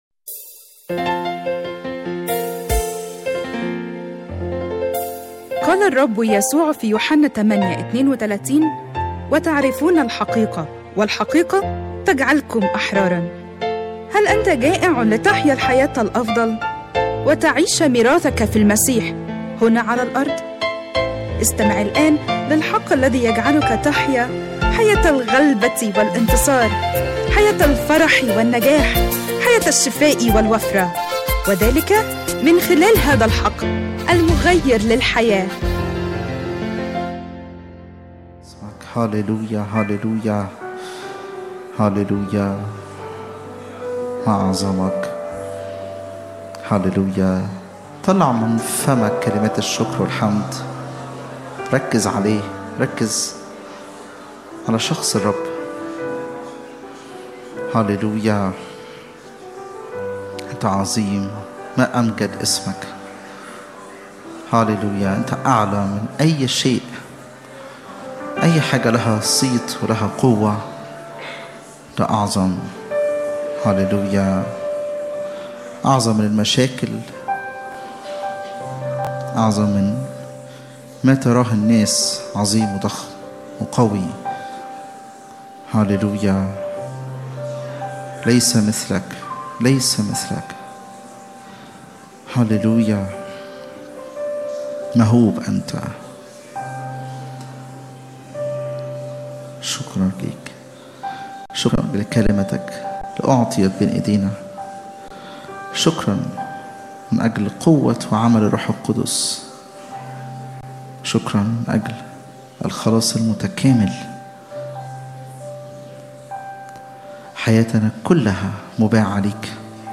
اجتماع الثلاثاء